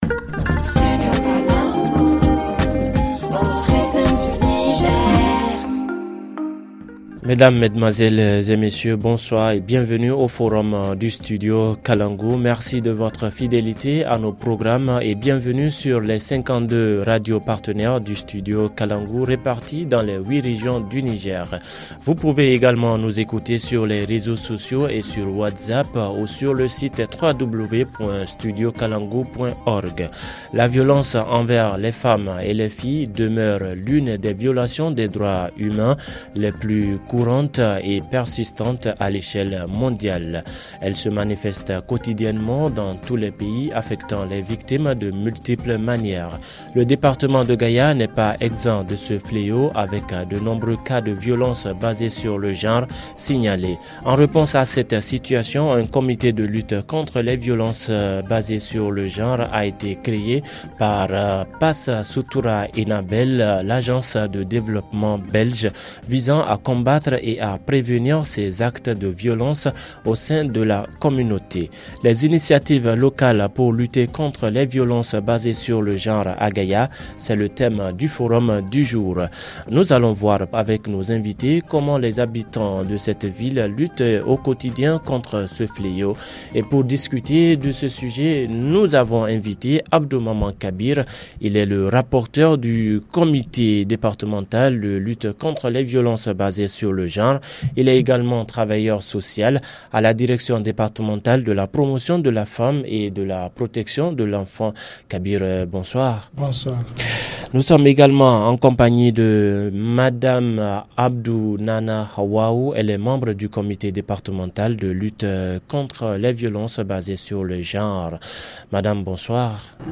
Dans ce forum il s’agit de voir avec nos invités comment les habitants de cette ville luttent au quotidien contre ce fléau.